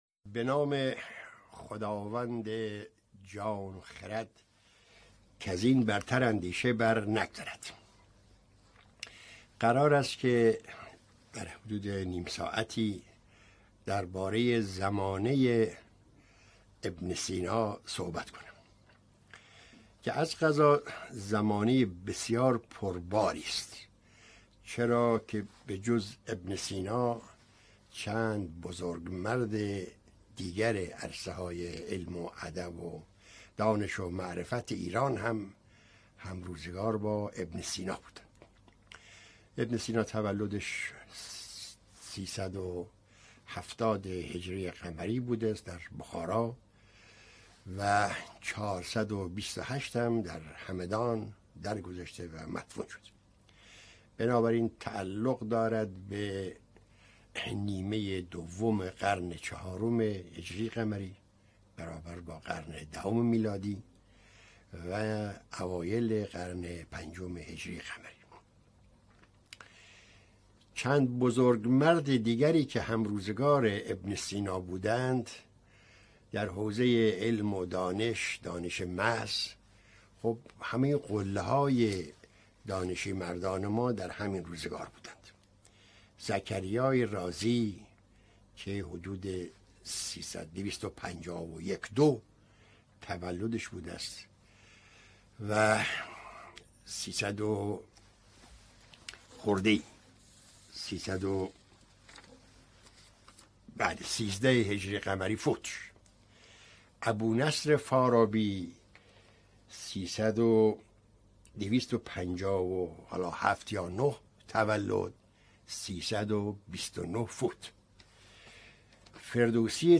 این درس‌گفتار به صورت مجازی از اینستاگرام شهر کتاب پخش شد.